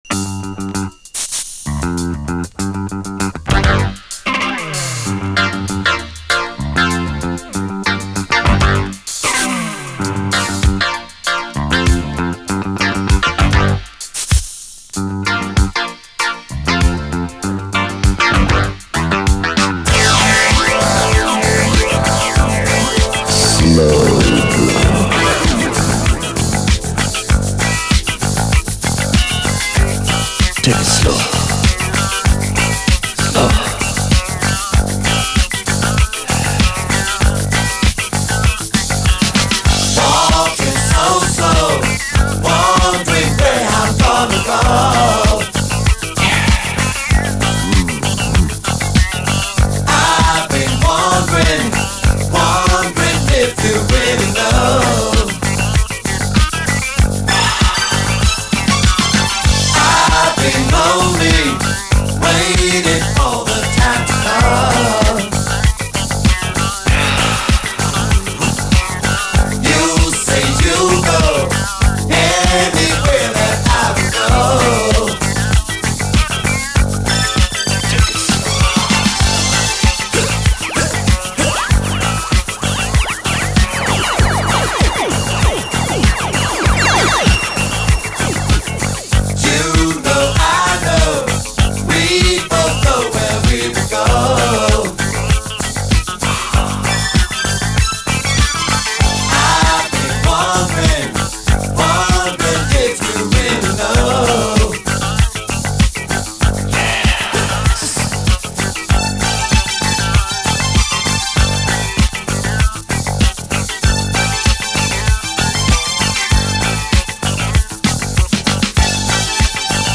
DISCO
コズミック・クラシックである妖しいファンキー・ブギー